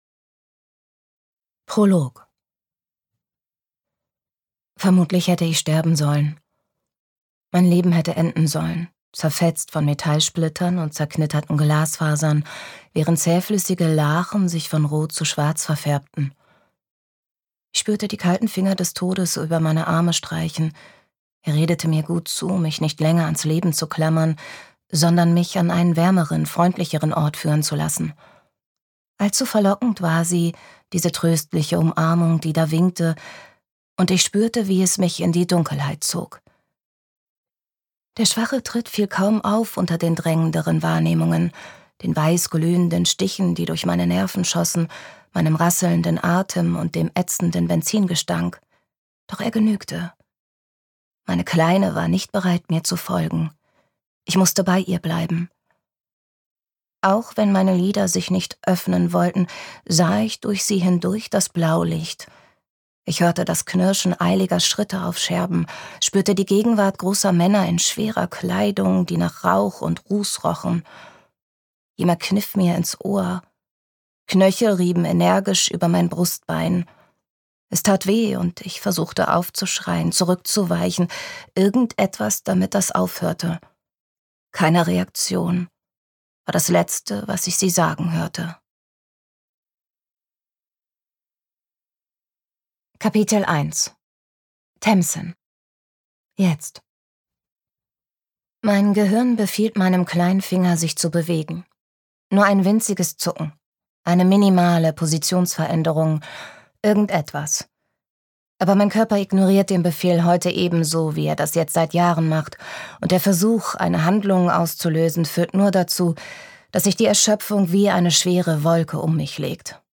Ich kann euch hören - Natalie Chandler | argon hörbuch
Gekürzt Autorisierte, d.h. von Autor:innen und / oder Verlagen freigegebene, bearbeitete Fassung.